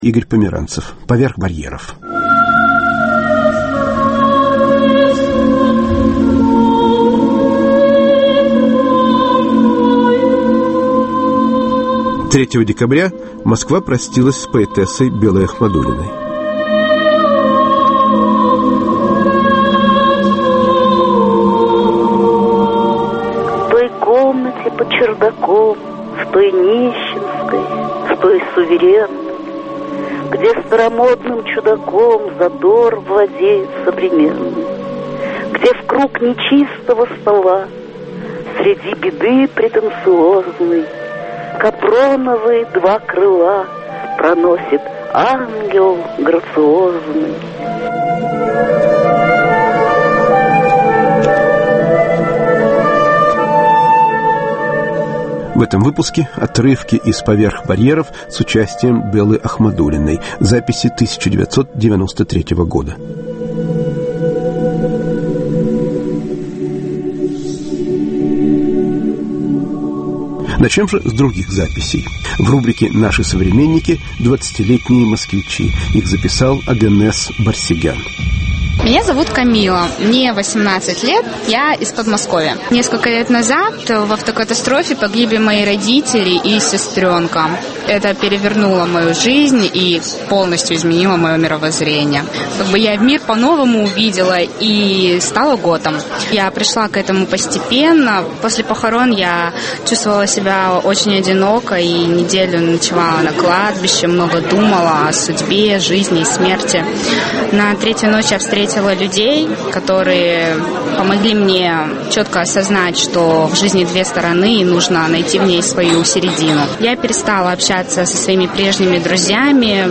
Монологи двадцатилетних москвичей: гота, геймера, панка и хип-хоп музыканта.